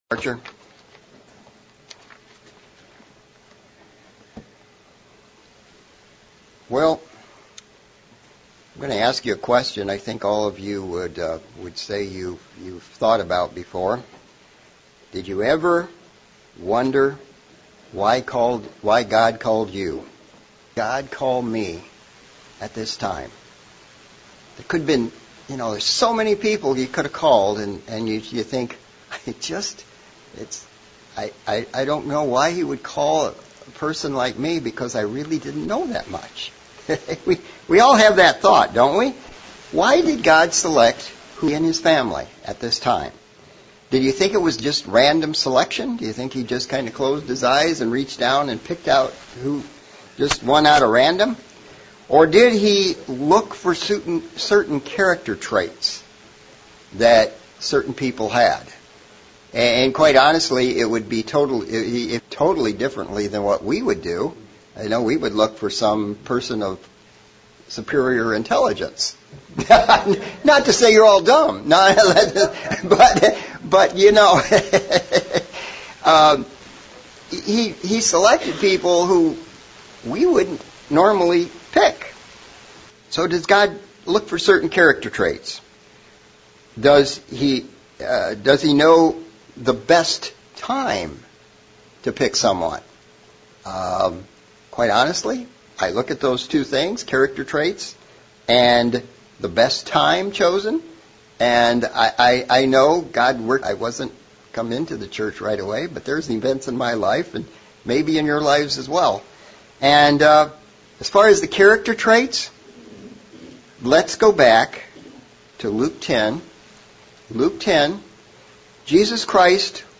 Sermon looking at how God looks at the proud and works with the humble. Who God works and does not work with.